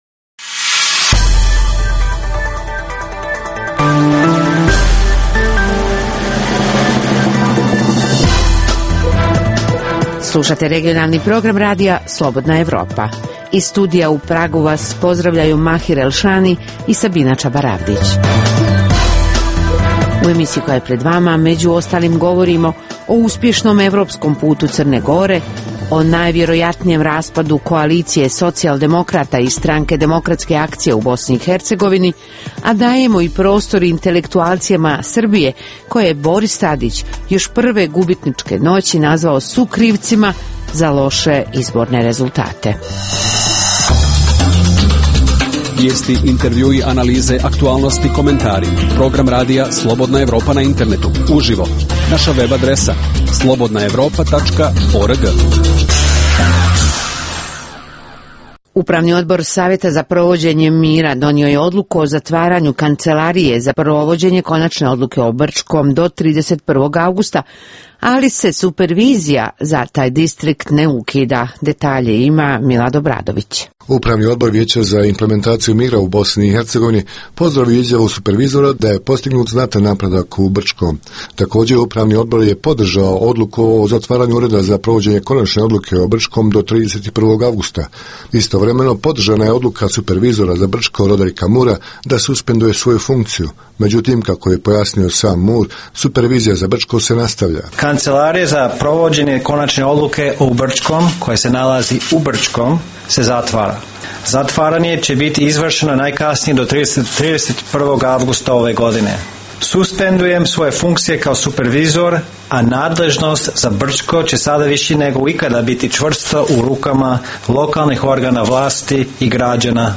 Intervju sa Florianom Bieberom